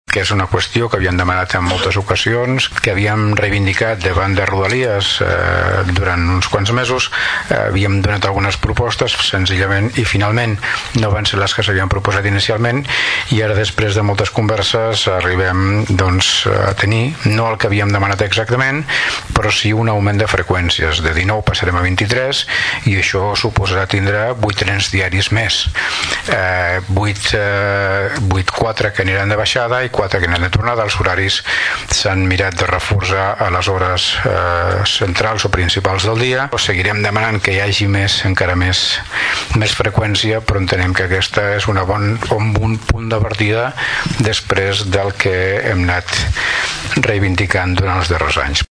L’alcalde de Tordera, Joan Carles Garcia destaca l’augment de freqüències que suposarà aquest canvi.
alcalde-rodalies-1.mp3